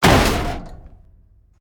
main Divergent / mods / Soundscape Overhaul / gamedata / sounds / ambient / soundscape / underground / under_20.ogg 47 KiB (Stored with Git LFS) Raw Permalink History